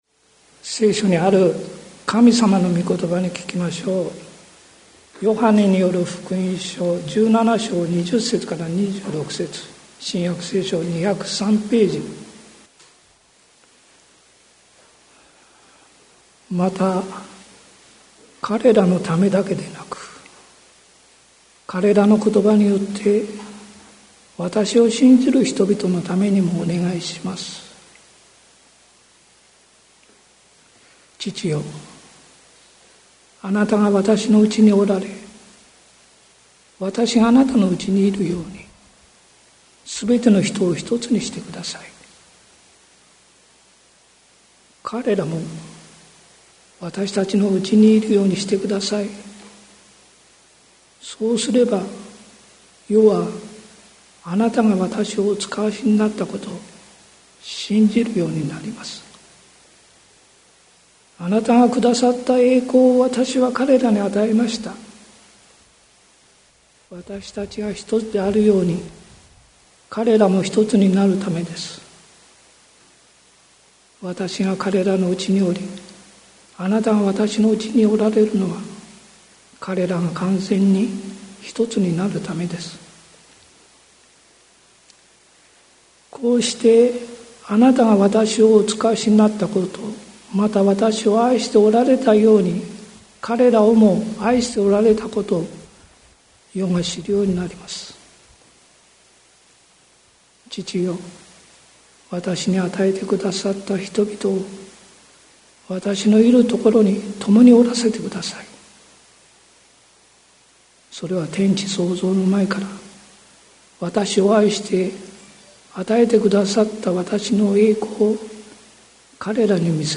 2023年10月01日朝の礼拝「主イエスの愛の祈り」関キリスト教会
説教アーカイブ。